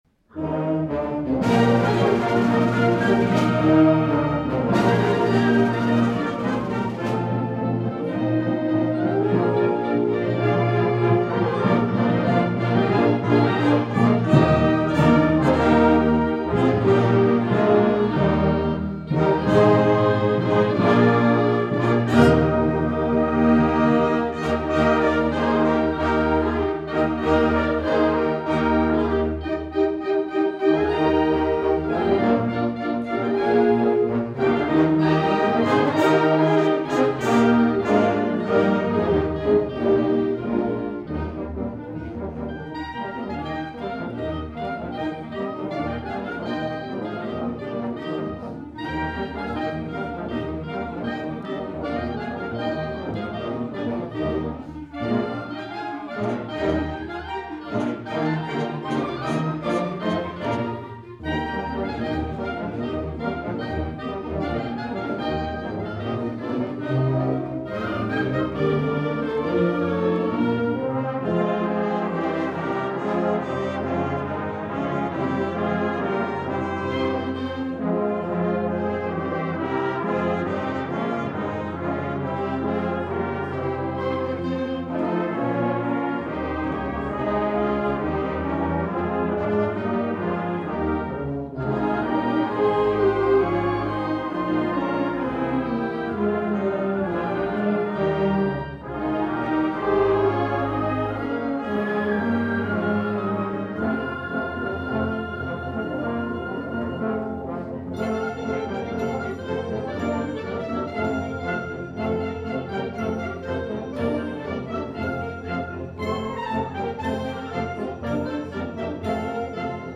2011 Winter Concert